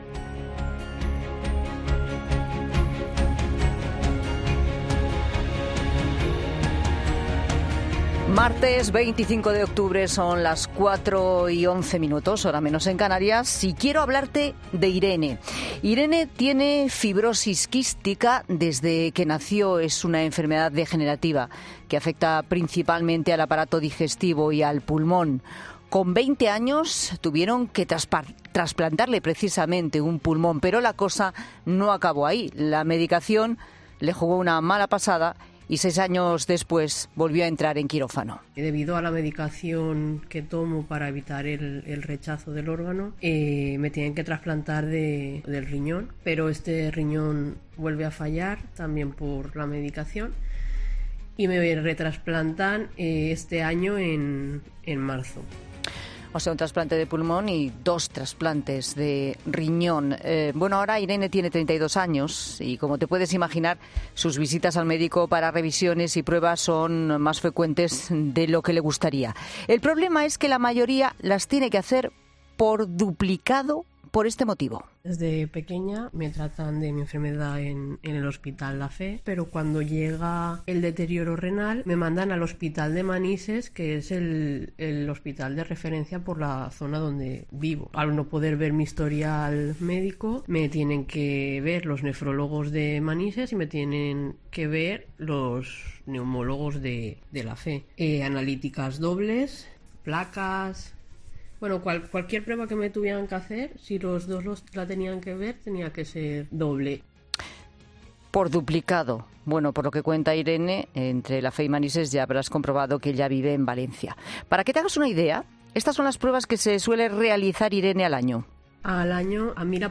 Una paciente y dos médicos hablan en 'La Tarde' sobre el no desplazamiento del historial clínico en España